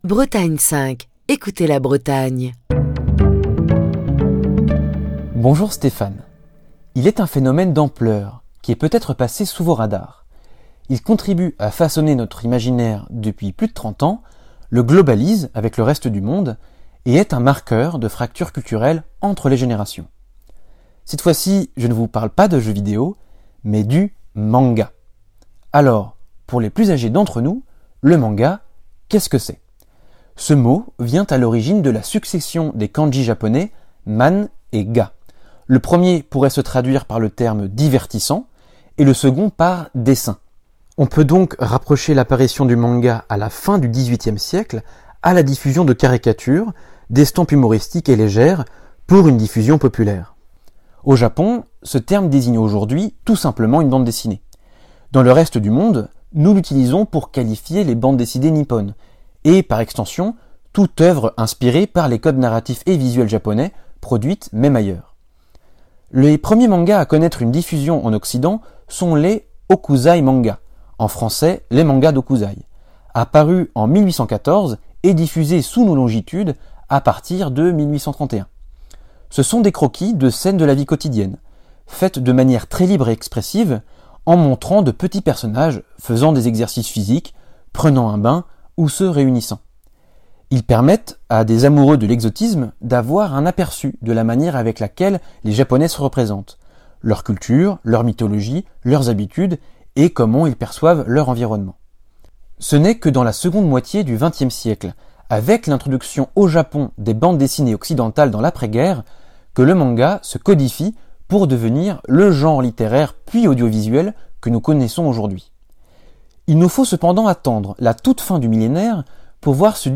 Chronique du 29 janvier 2024.